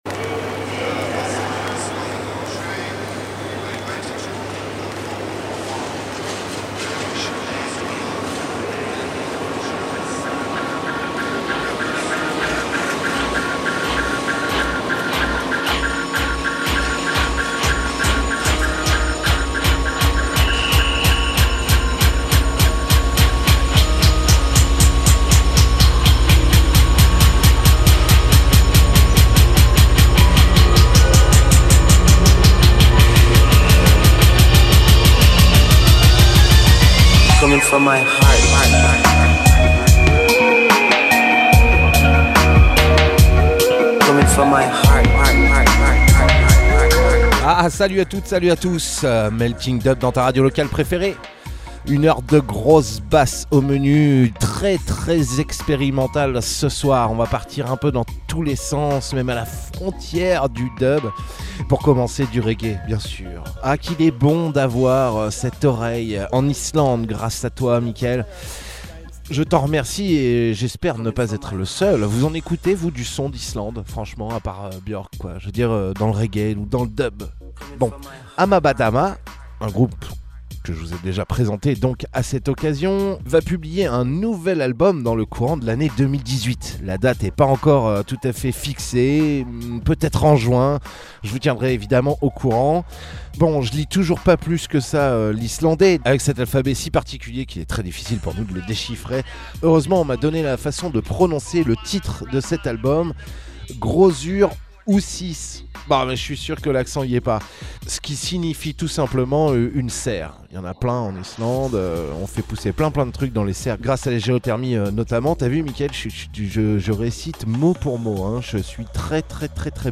Télécharger en MP3 Dub music is an experience…
bass music , dub , musique , musique electronique , reggae